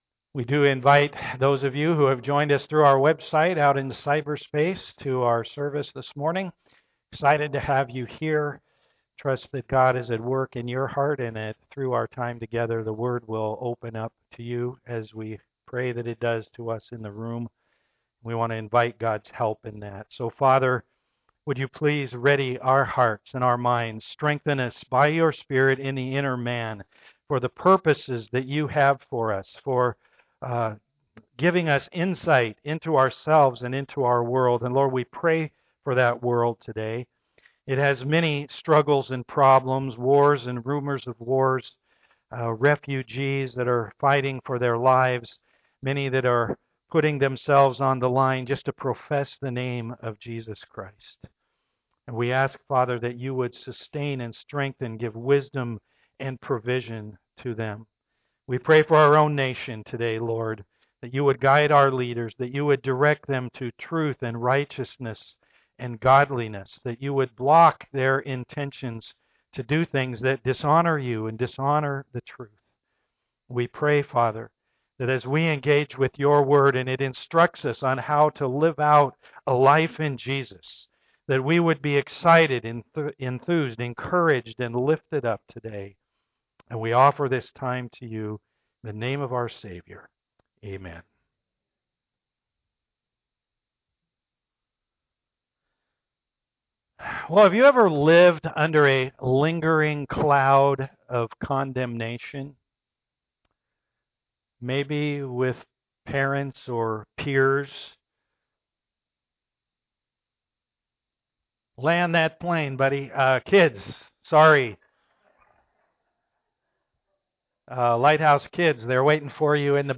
Romans 8:1-11 Service Type: am worship We battle the flesh